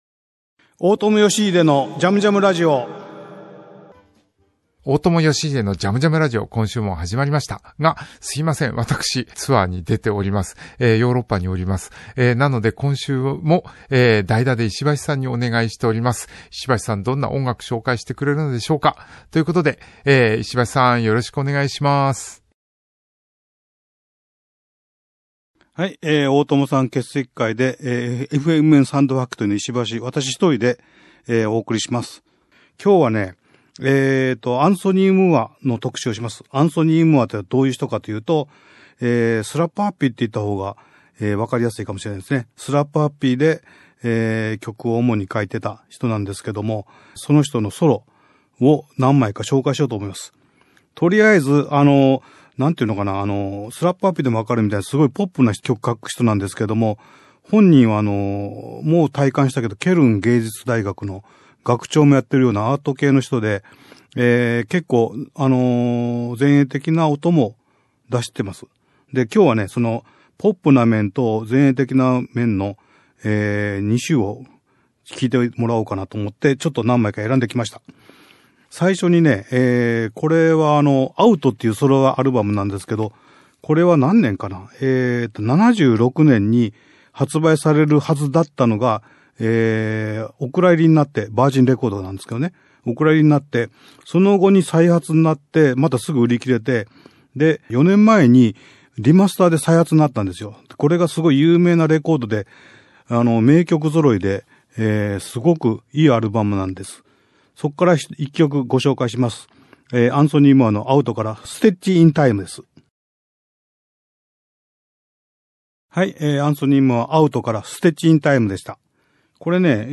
音楽家・大友良英がここでしか聞けないような変わった音楽から昔懐かしい音楽に至るまでのいろんな音楽とゲストを招いてのおしゃべりや、リスナーの皆さんからのリクエストやメッセージにもお答えしていくこの番組ならではのオリジナルなラジオ番組です。